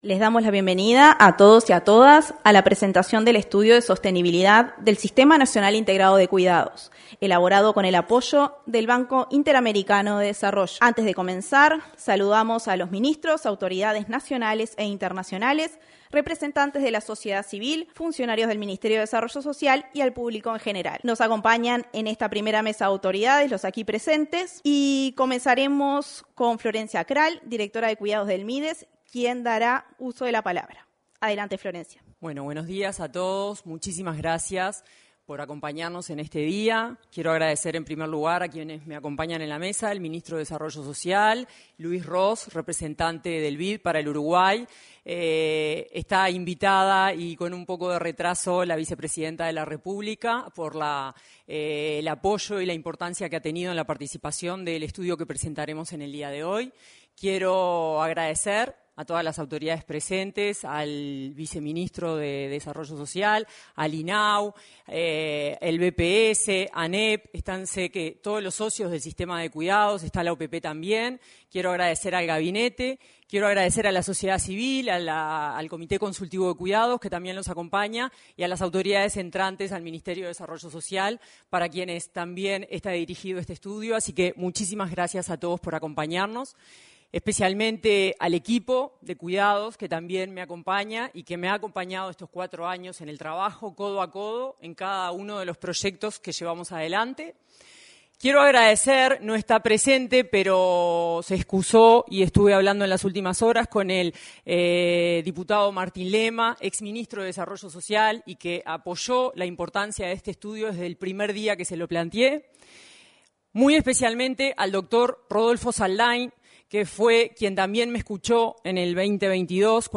Presentación del Estudio de Sostenibilidad del Sistema de Cuidados 28/01/2025 Compartir Facebook X Copiar enlace WhatsApp LinkedIn Este martes 28, fue presentado, en el salón de de actos de la Torre Ejecutiva, el Estudio de Sostenibilidad del Sistema de Cuidados.